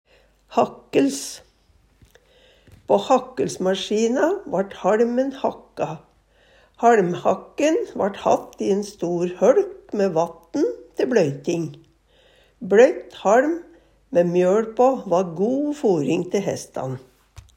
hakkels - Numedalsmål (en-US)